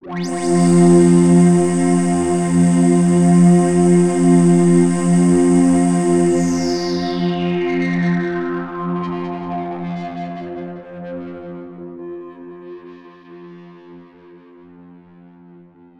Here’s a test with the same 3 sounds on both hardware and software, all recorded into Digitakt II.
Its still got the same overall character as the hardware, but there’s clearly some differences in the software version, probably partly due to the updated effects (the software reverb actually doesnt glitch like the hardware does).